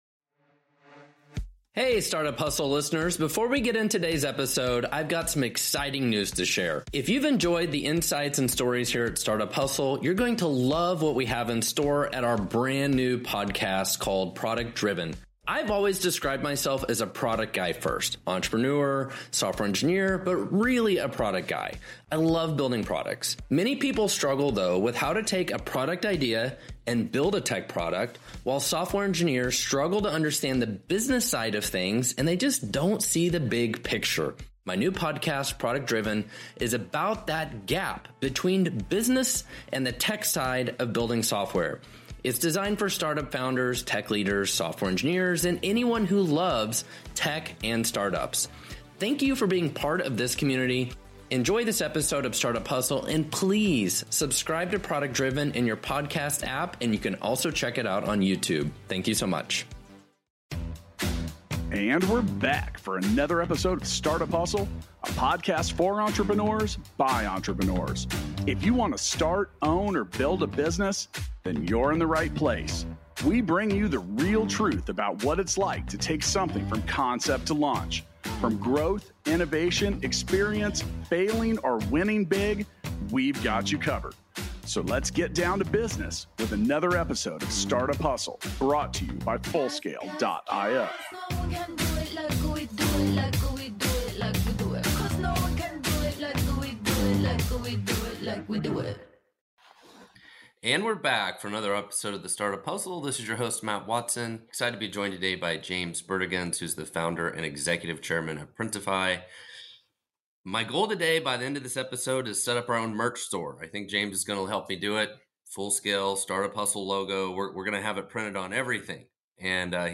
You'll discover the challenges of managing a distributed remote team across 30 countries, policing copyright infringement on a massive scale, and ensuring quality control when every order differs. This engaging conversation provides fascinating insights into building a global tech company from the ground up.